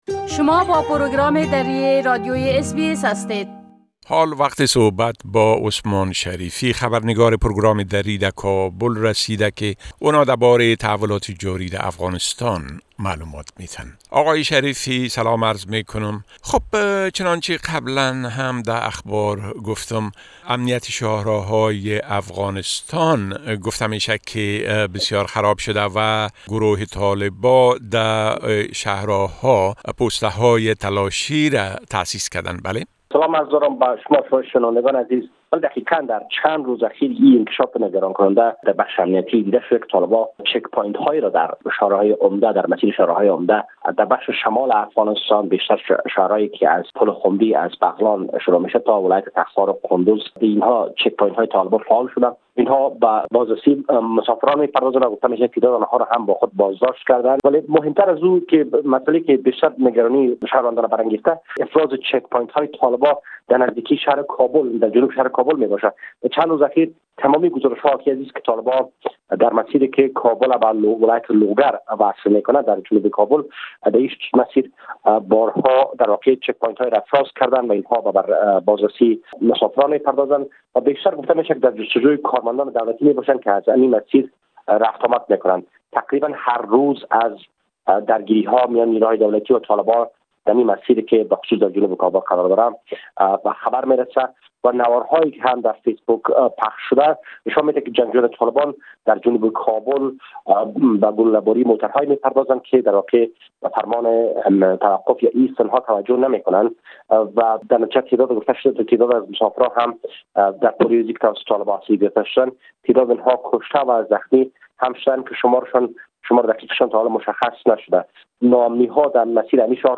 گزارش كامل خبرنگار ما در كابل بشمول اوضاع امنيتى٬ و تحولات مهم ديگر در افغانستان را در اينجا شنيده ميتوانيد.